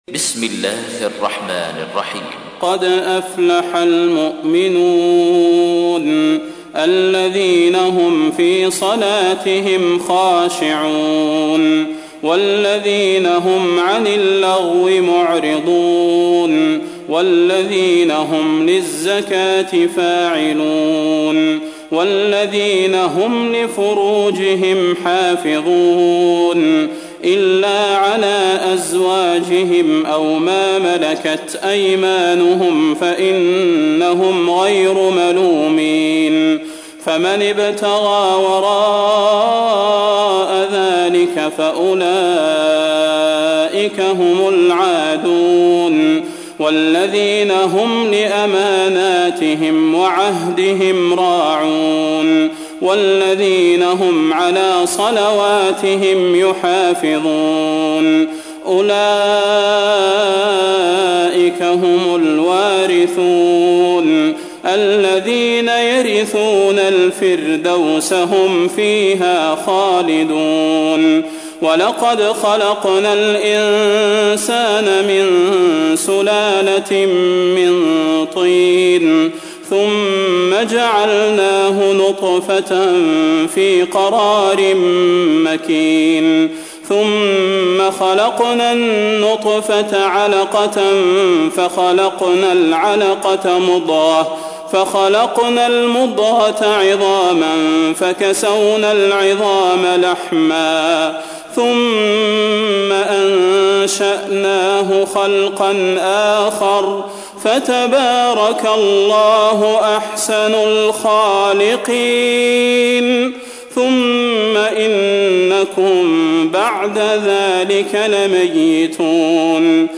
تحميل : 23. سورة المؤمنون / القارئ صلاح البدير / القرآن الكريم / موقع يا حسين